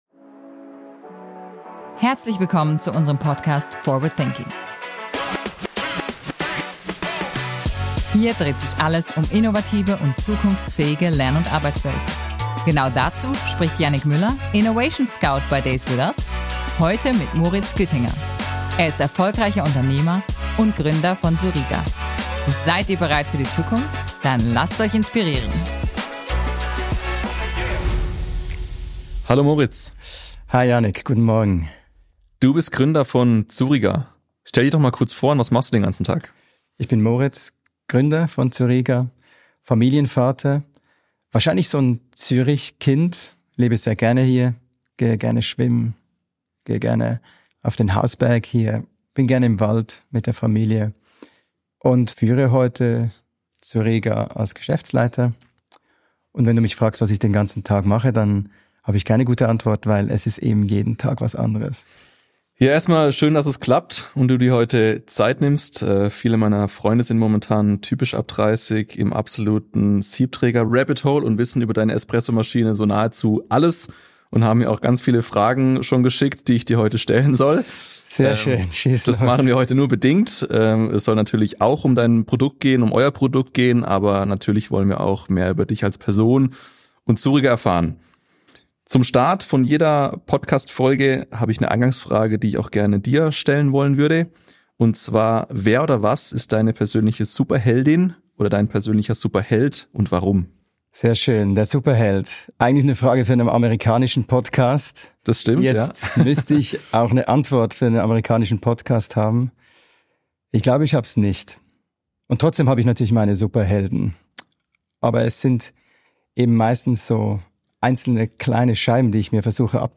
Im Gespräch geht es um unternehmerische Prinzipien, um Lokalität und Unternehmenskultur, aber auch um Projekte, die ordentlich schiefgegangen sind. Ausserdem klären wir folgende Fragen: Wie kann Innovation in einem wachsenden Unternehmen beibehalten werden?